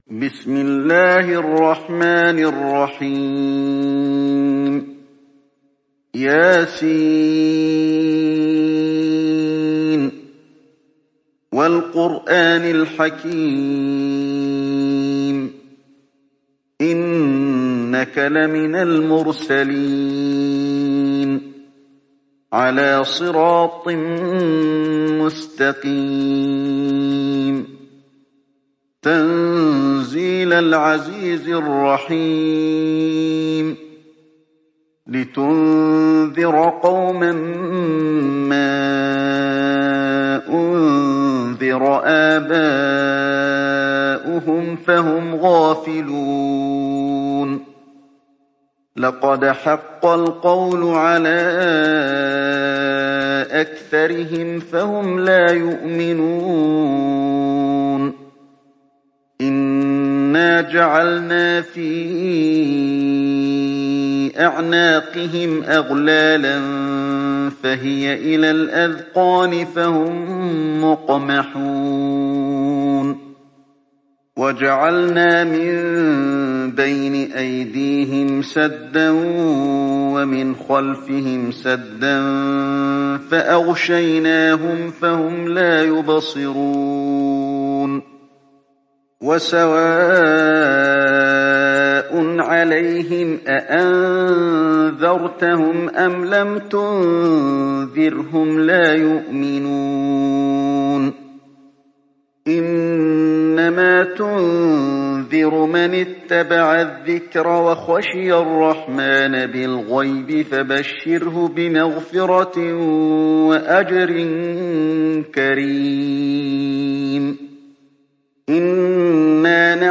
تحميل سورة يس mp3 بصوت علي الحذيفي برواية حفص عن عاصم, تحميل استماع القرآن الكريم على الجوال mp3 كاملا بروابط مباشرة وسريعة